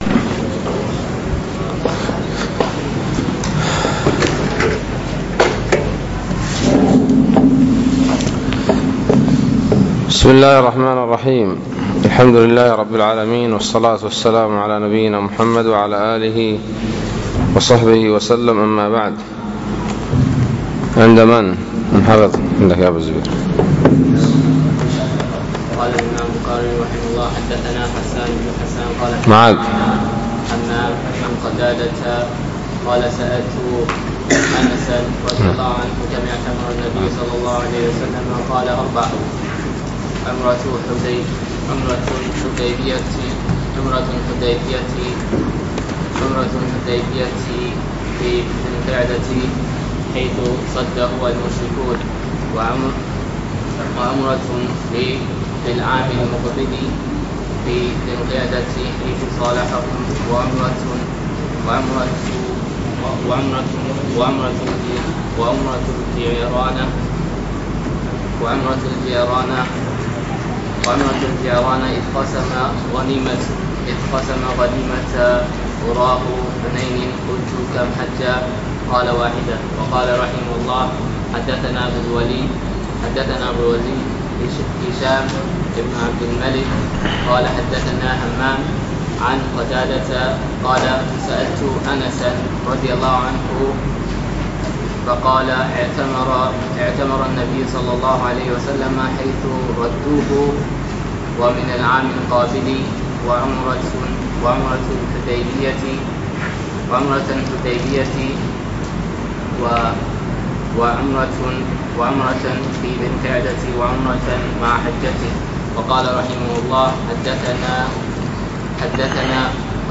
الدرس الرابع : بَاب عُمْرَةٍ فِي رَمَضَانَ